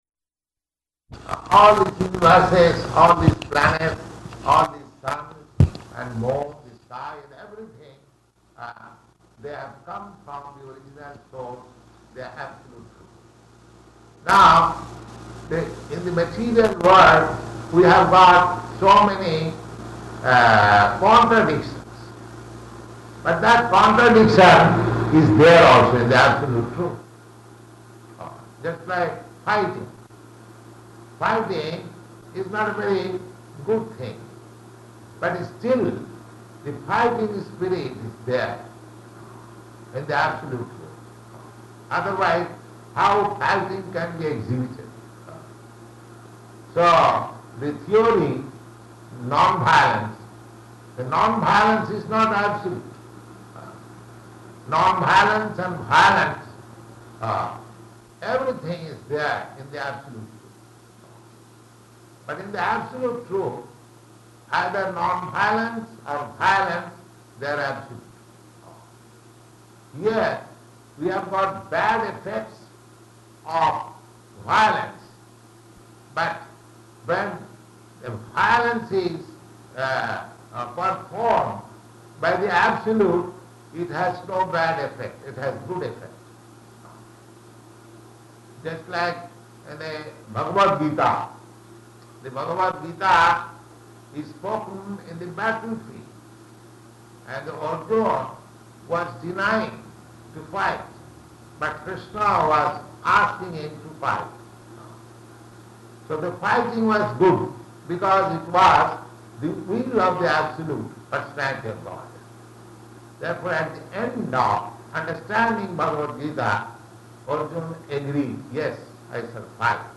Type: Purport
[Poor audio]